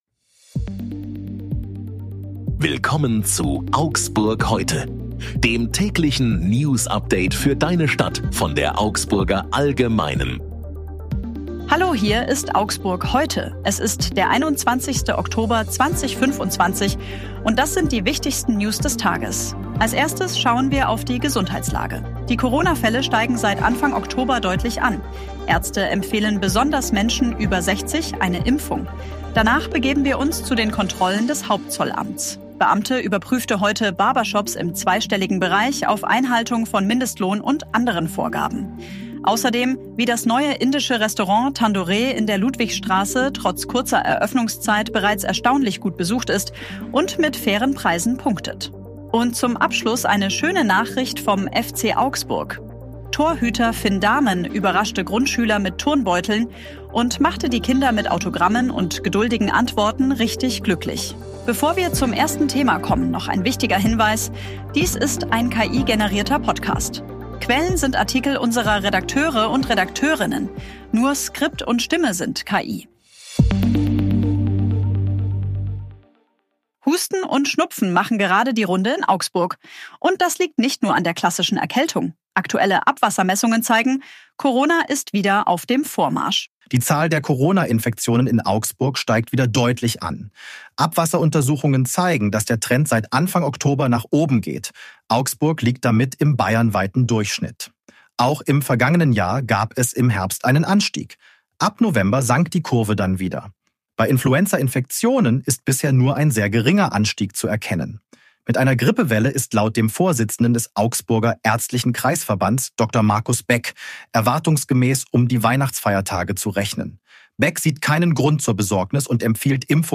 Hier ist wieder das tägliche Newsupdate für deine Stadt.
Nur Skript und Stimme sind KI.